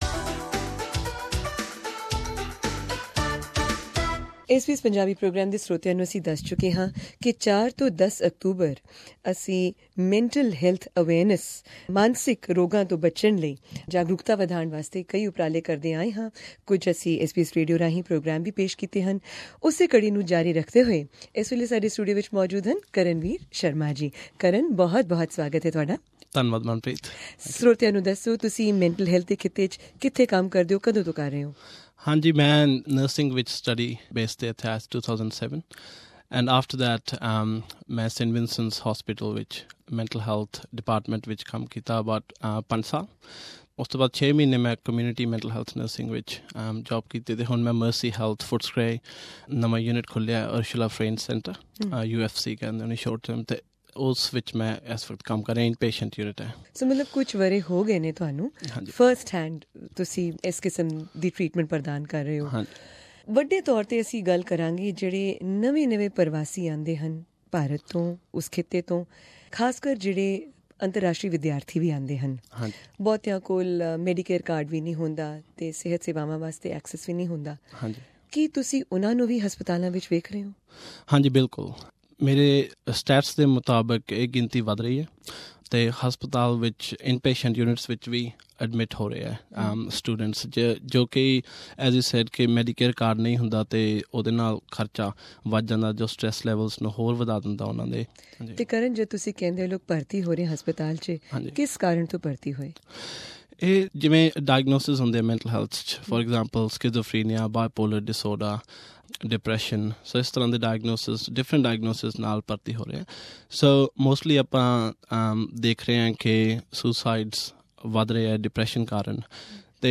SBS Melbourne studios